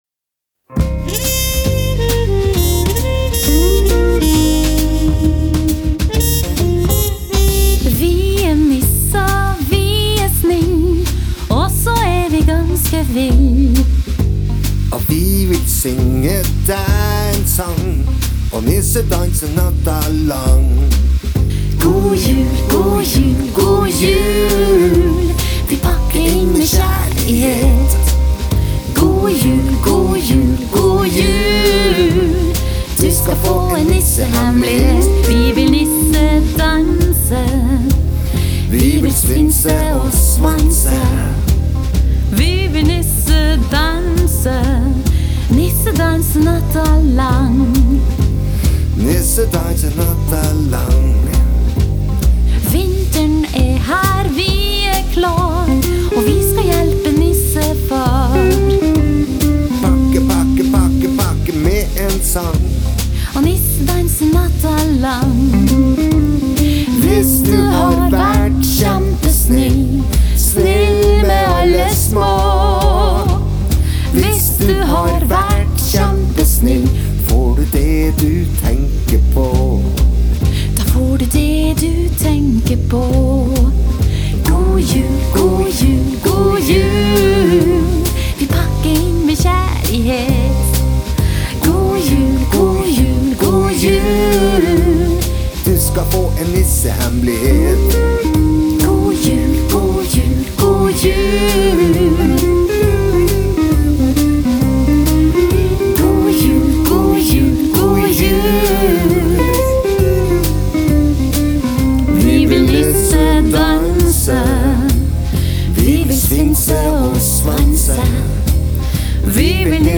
Alle gitarer
De har også en avslappet laidback feel!